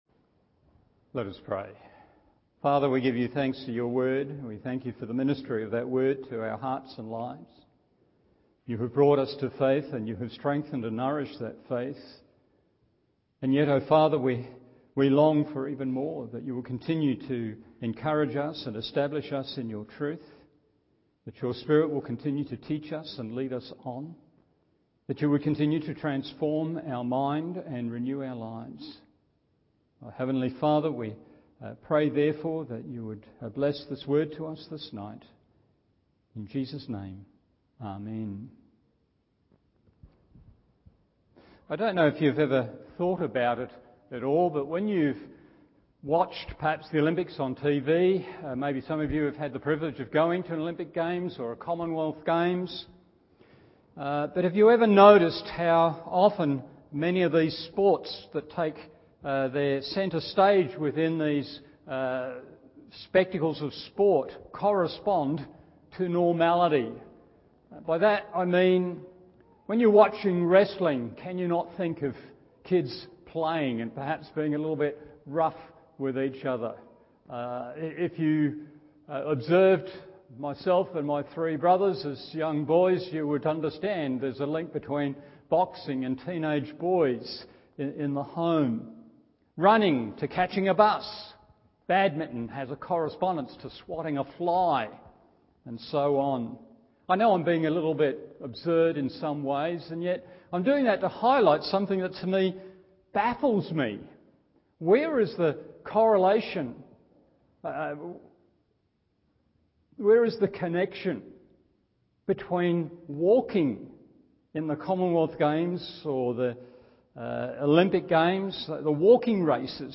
Evening Service 1 Thessalonians 4:1-2 1. Compelled by Love 2. Constrained by Duty 3.